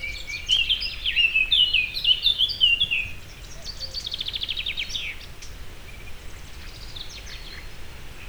baratposzata00.08.wav